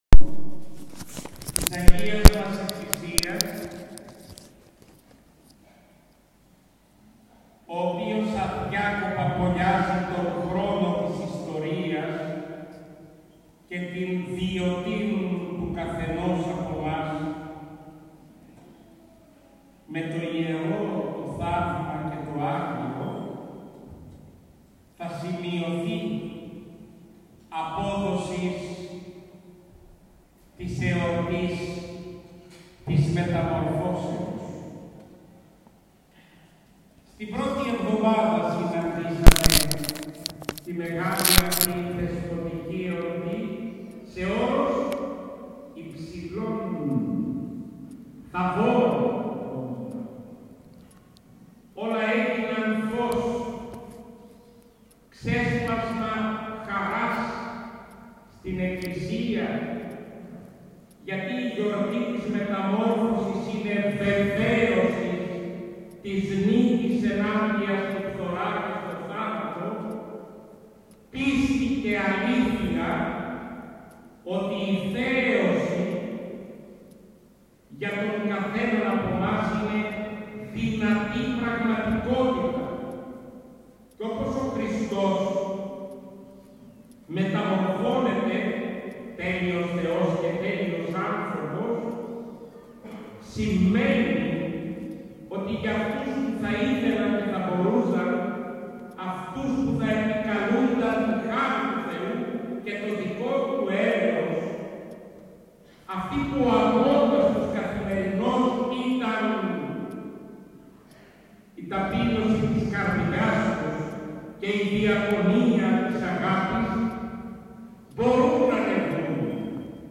Στον Πεντάλοφο Θεσσαλονίκης, στον Ιερό Ναό Κοιμήσεως Θεοτόκου ιερούργησε ο Σεβασμιώτατος Μητροπολίτης Νεαπόλεως και Σταυρουπόλεως κ. Βαρνάβας την Κυριακή 13 Αυγούστου 2023.
Ακούστε το κήρυγμα του Μητροπολίτη Νεαπόλεως και Σταυρουπόλεως: